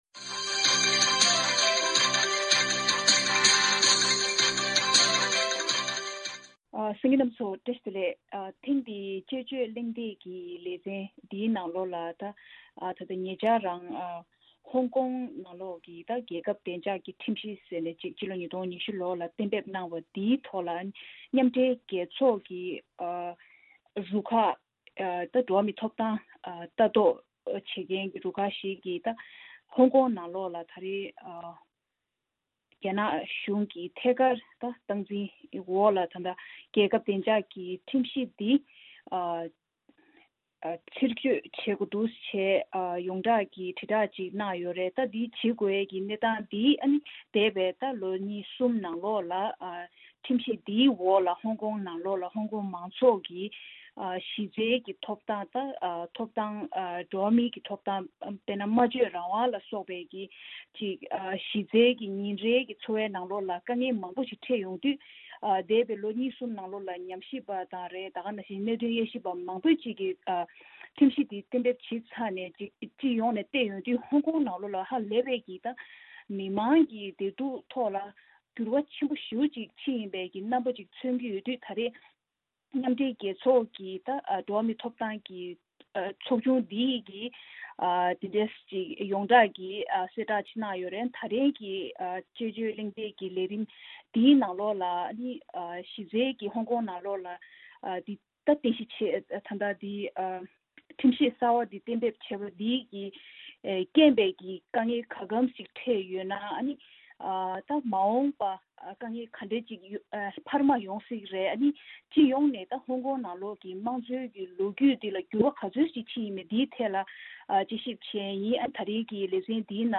དཔྱད་བརྗོད་གླེང་སྟེགས་ཀྱི་ལས་རིམ